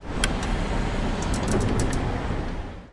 购物中心停车场 " 公园障碍物
描述：录音：出口栏杆抬起来让汽车出去的声音。 录音：用Zoom H4在Glories Shopping Mall的停车场录的，